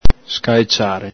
Alto Vic.